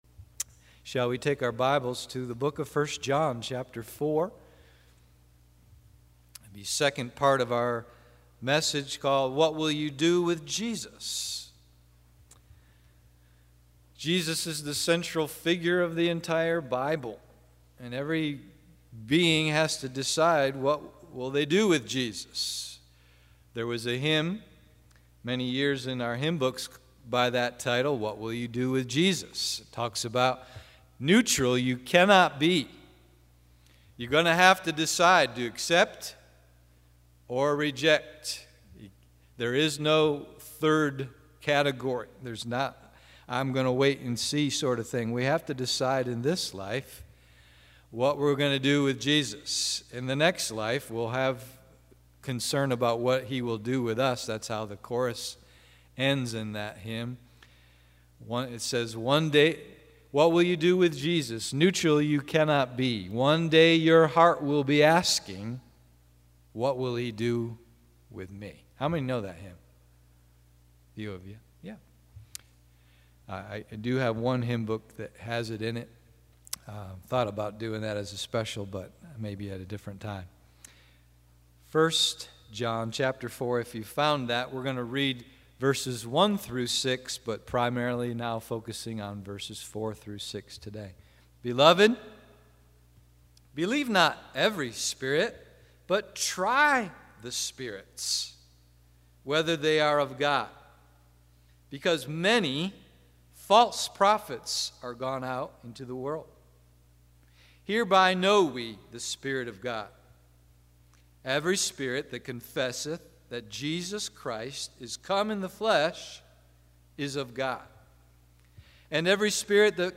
What Will You Do with Jesus (Part 2) AM Service